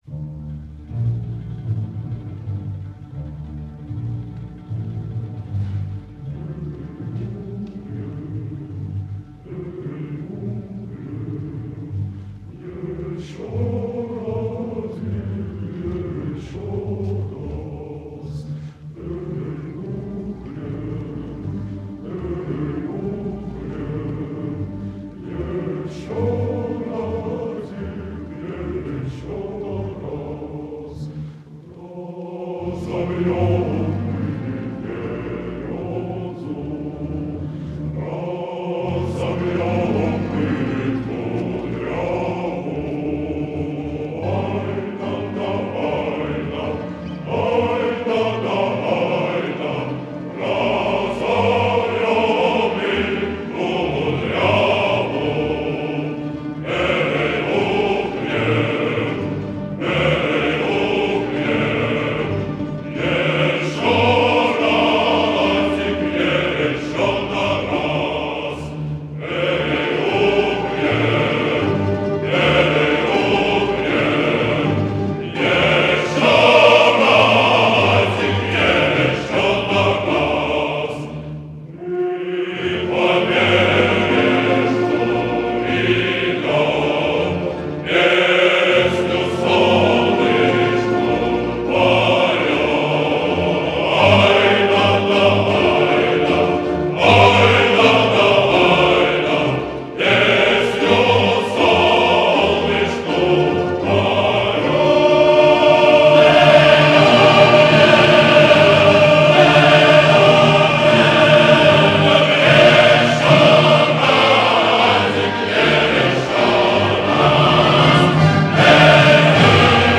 Хоровое исполнение